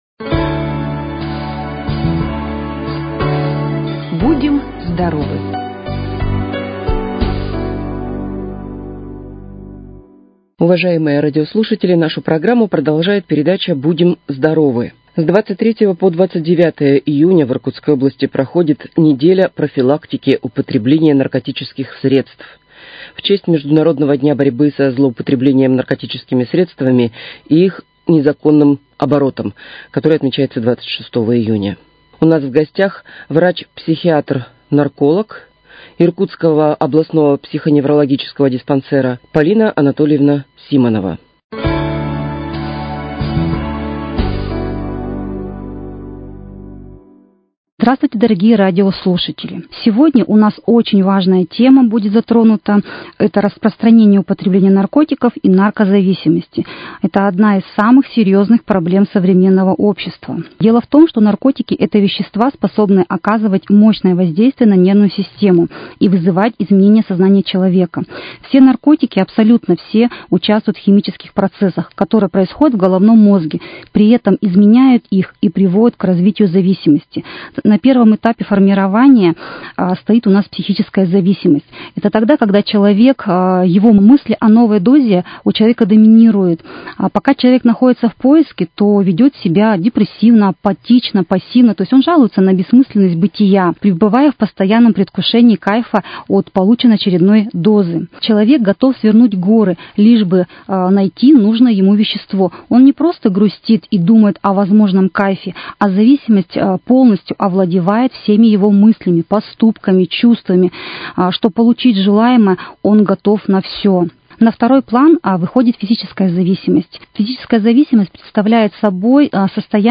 Предлагаем вашему очередную передачу из серии, которая готовится совместно с областным государственным бюджетным учреждением здравоохранения «Иркутский областной центр общественного здоровья и медицинской профилактики».